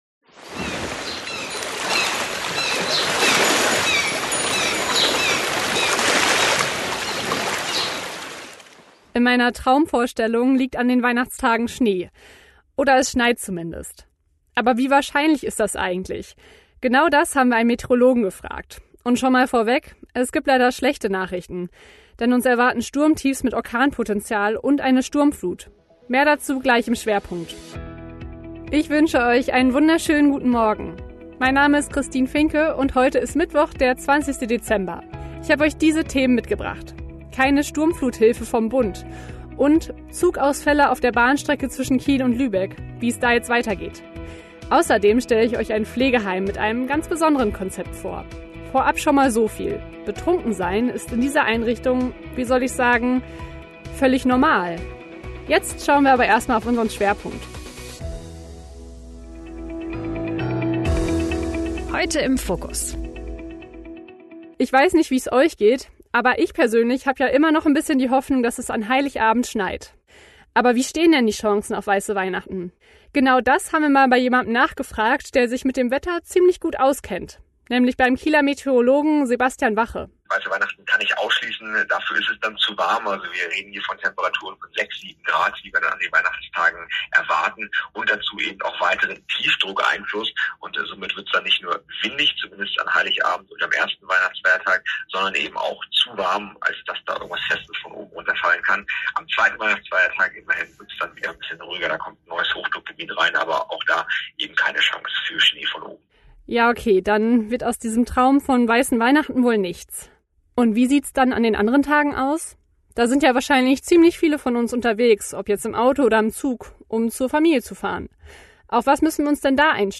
Guten Morgen SH — Dein neuer News-Podcast für Schleswig-Holstein